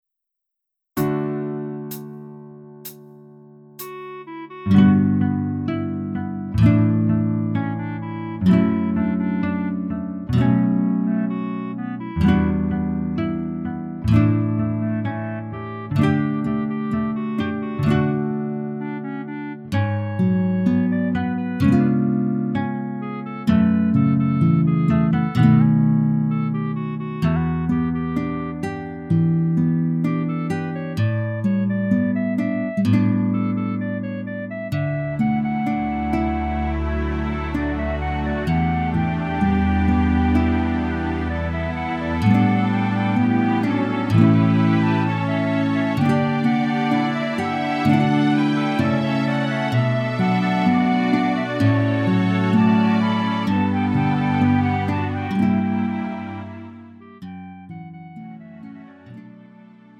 음정 원키 3:39
장르 가요 구분 Lite MR
Lite MR은 저렴한 가격에 간단한 연습이나 취미용으로 활용할 수 있는 가벼운 반주입니다.